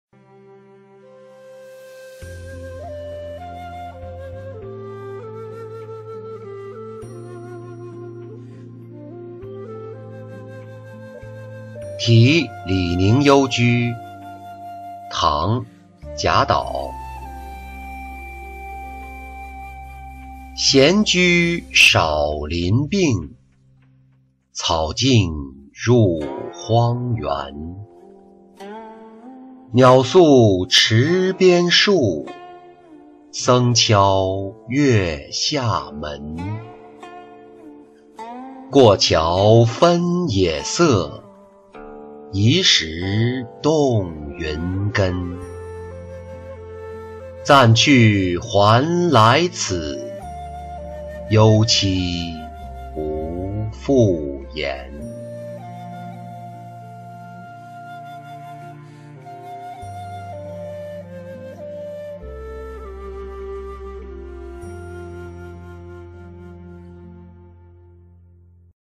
题李凝幽居-音频朗读